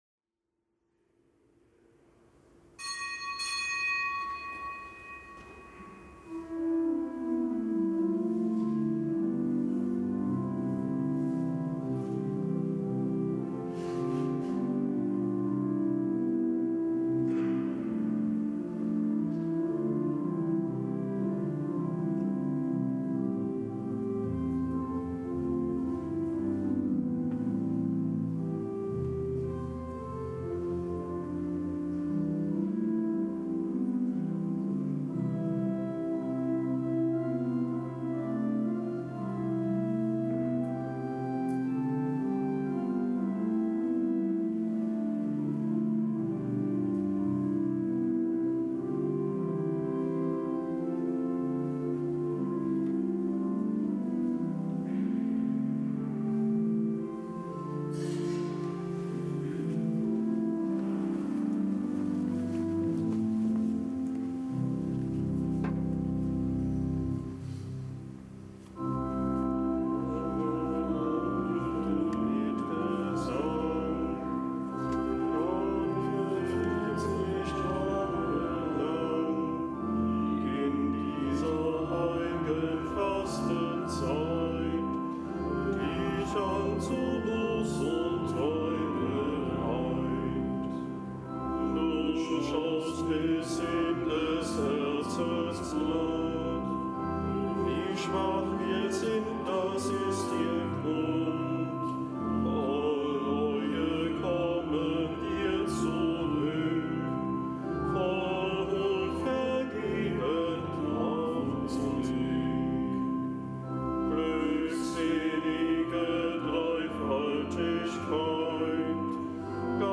Kapitelsmesse aus dem Kölner Dom am Donnerstag nach Aschermittwoch.